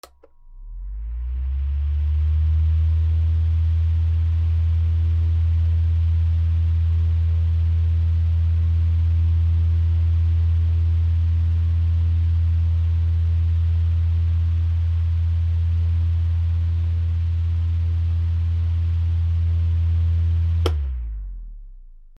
扇風機